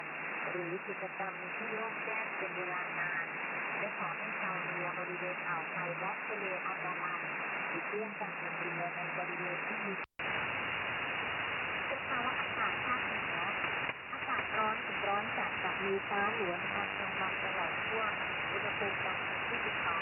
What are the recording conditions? Reception on shortwave Mode: USB BW: 2.8KHz